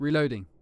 James reloading.wav